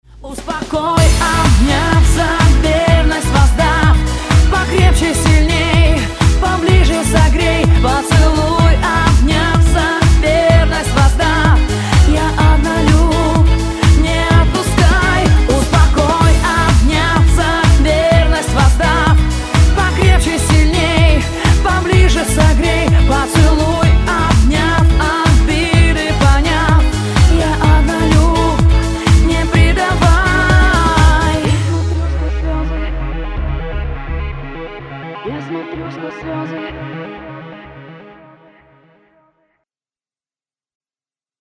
поп
женский вокал
попса